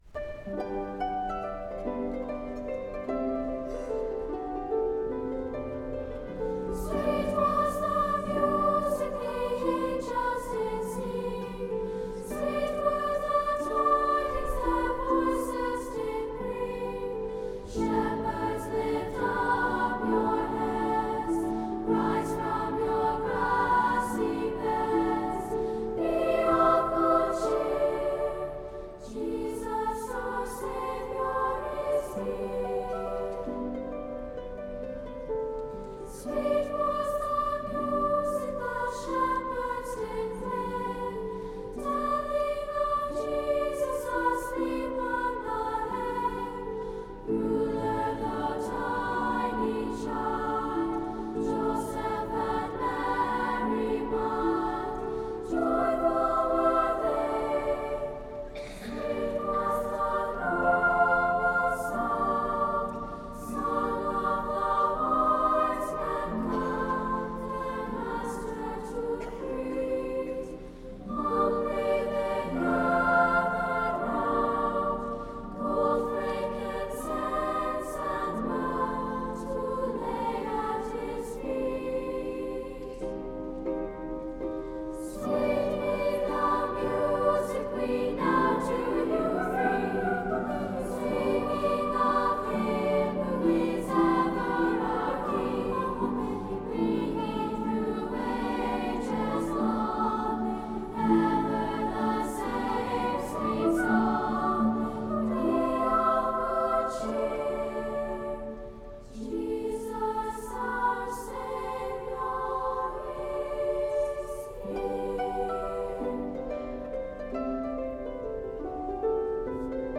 SSA, Christmas